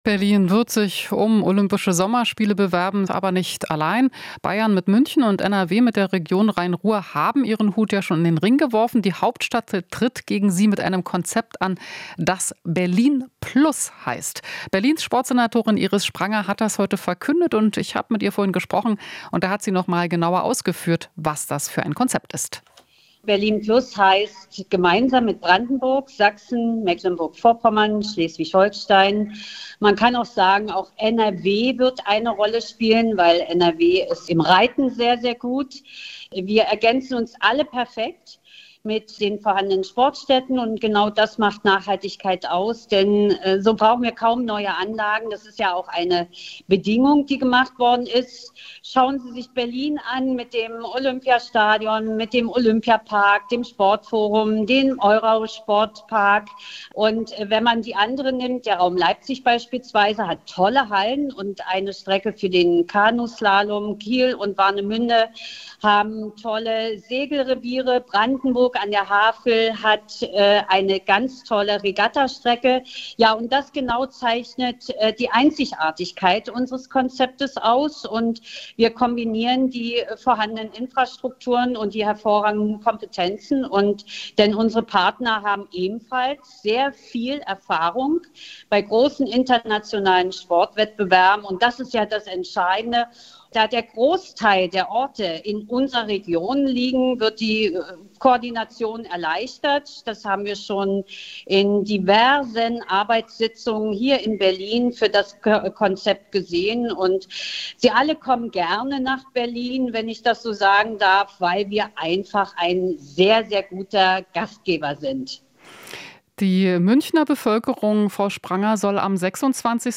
Interview - Spranger (SPD): Olympische Spiele große Chance für Berlin